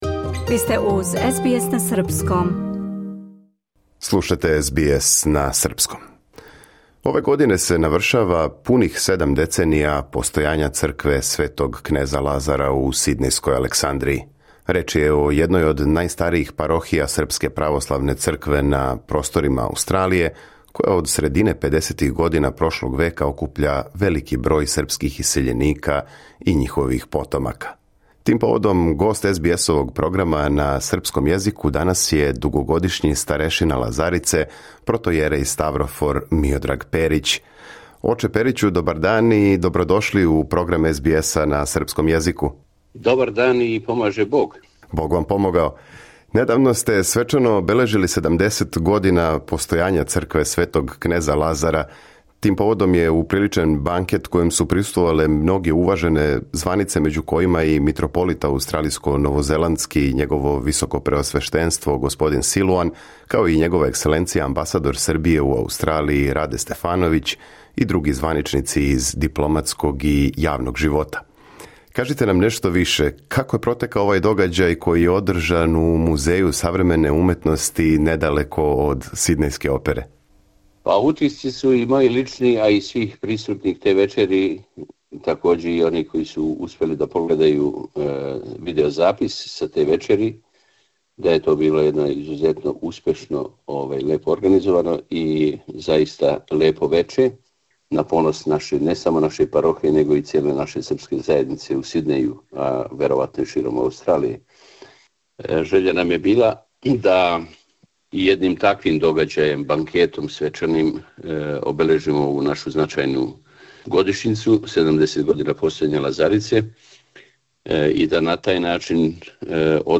For more stories, interviews, and news from SBS SERBIAN, explore our podcast collection here.